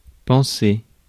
Ääntäminen
France: IPA: [pɑ̃.se]